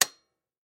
Звуки съемки видео
Звуковое оповещение включения камеры перед записью